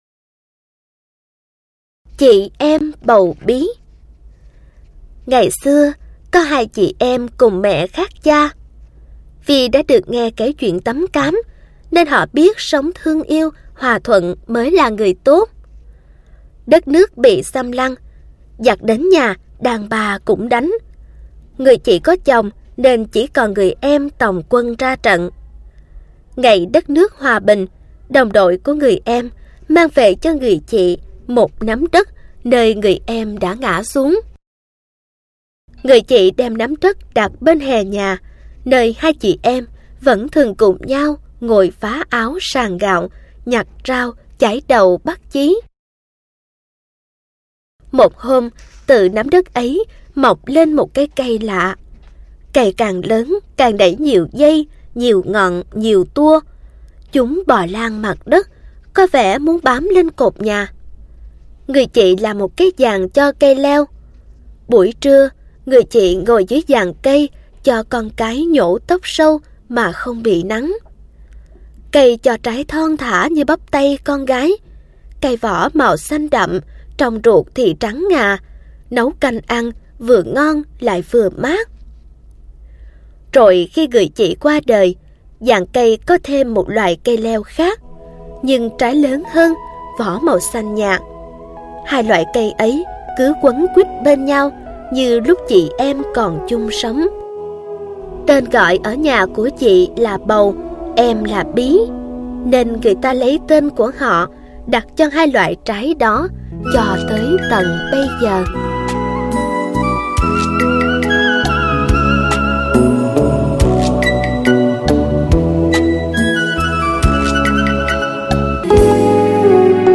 Sách nói | xóm đồ chơi